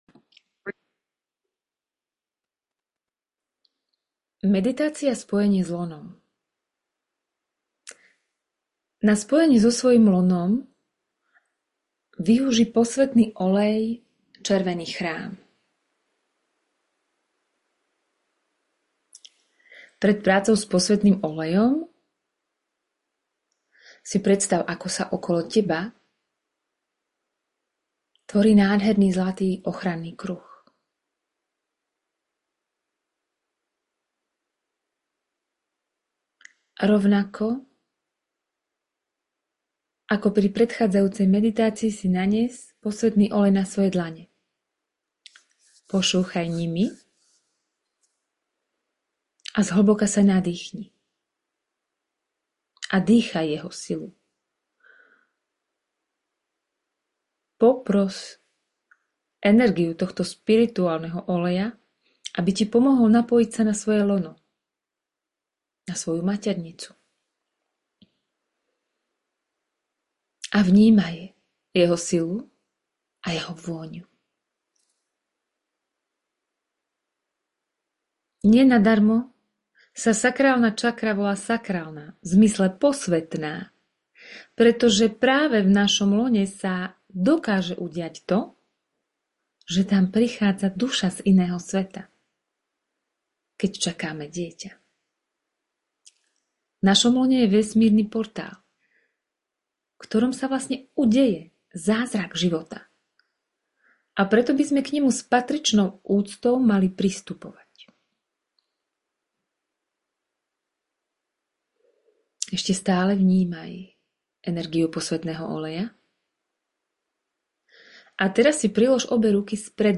meditacia-spojenie-s-lonom.mp3